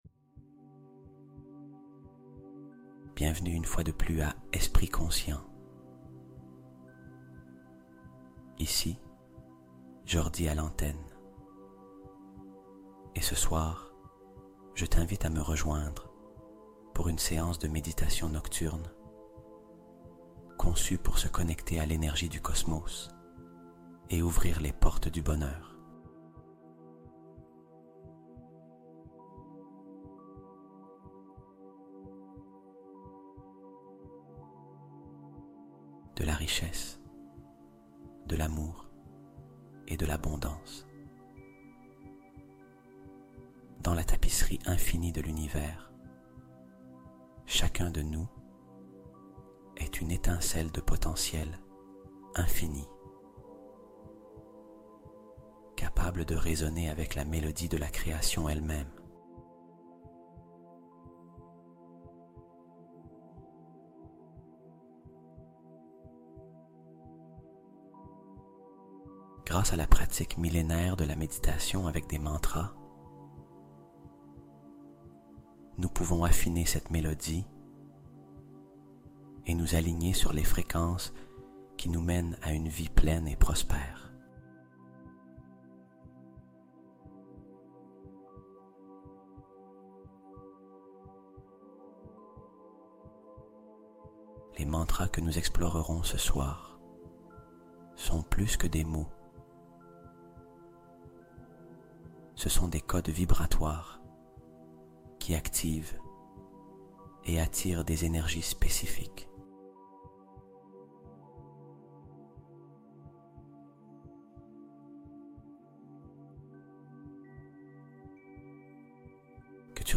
LES MANTRAS GARDÉS SECRETS DEPUIS 3000 ANS | 888 Hz Déclenche Miracles Et Bénédictions Instantanés